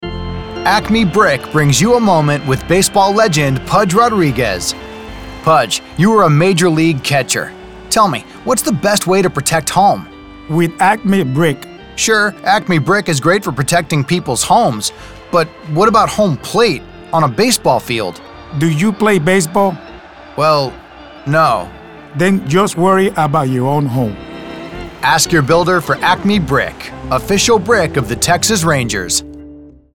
Male – Funny, Celebrity, Sports